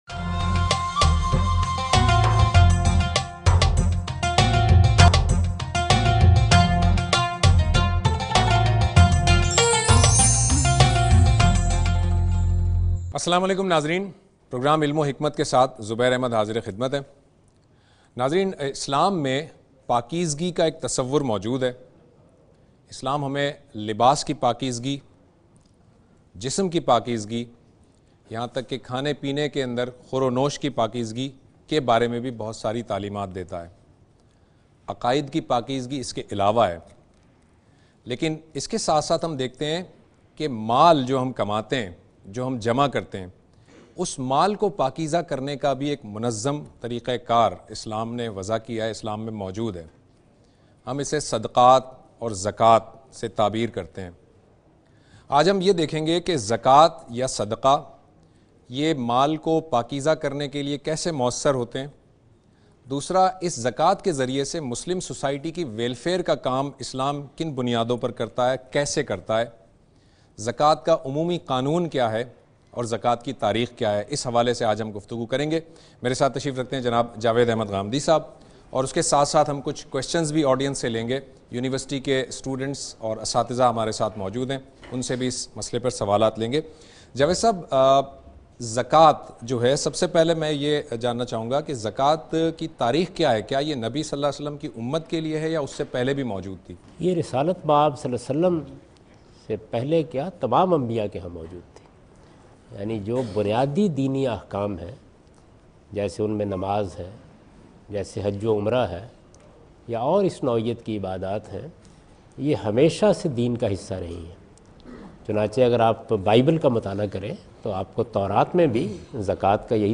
In this program Javed Ahmad Ghamidi speaks and answers the question about "Zakah and Charity" in Dunya News's program Ilm-o-Hikmat.